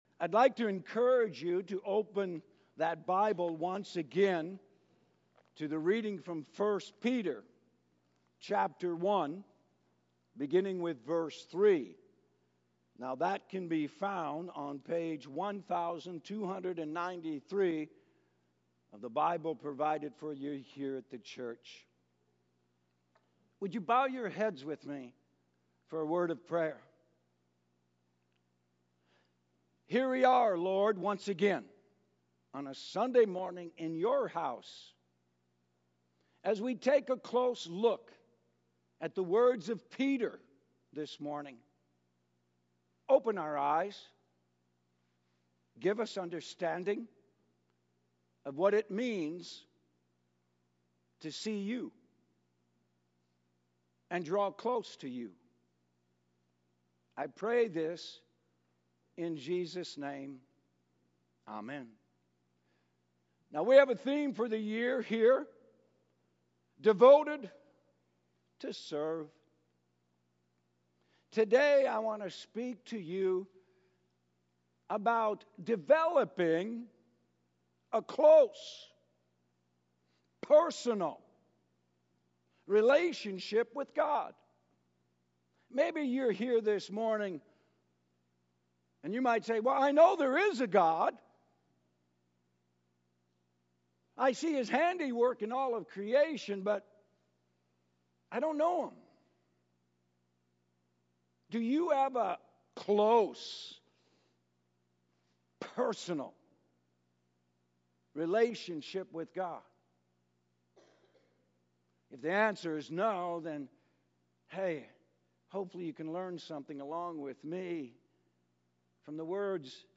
A message from the series "HIStory in 2024."